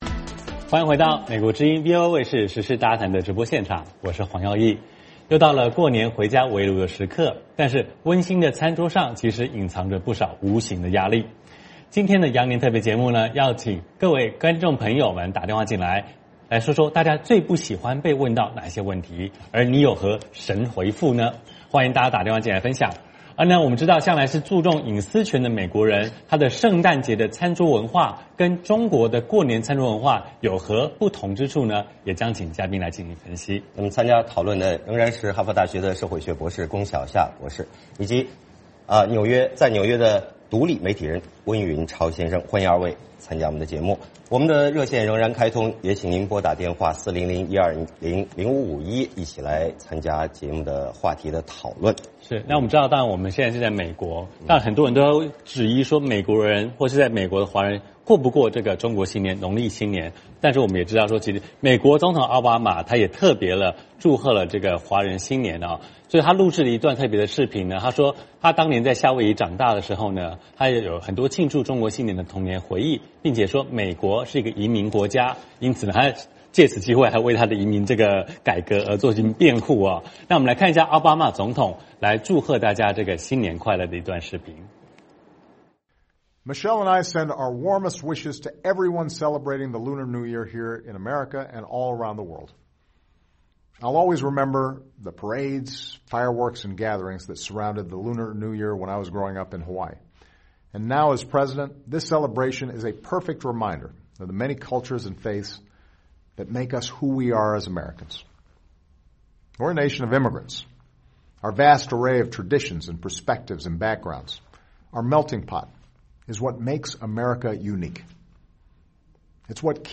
有何神回复?欢迎打电话进来分享。